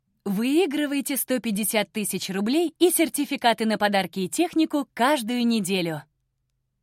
На этой странице собраны дикторские голоса, которые мы можем записать для вашего проекта.
Идеален для молодежной аудитории